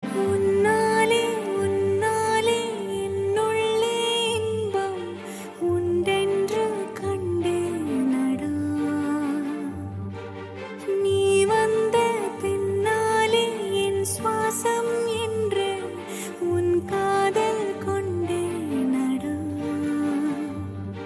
best flute ringtone download
romantic ringtone download